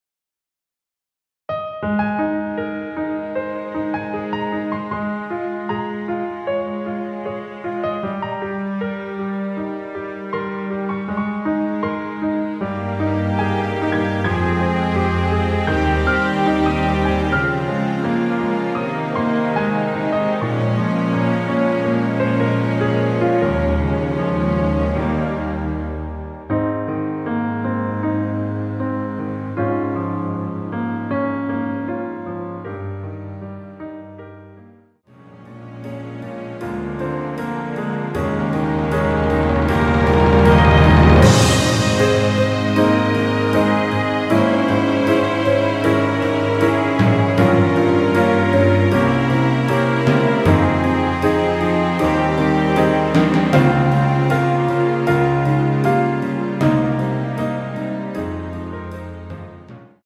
원키에서(-1)내린 MR입니다.
Ab
앞부분30초, 뒷부분30초씩 편집해서 올려 드리고 있습니다.
중간에 음이 끈어지고 다시 나오는 이유는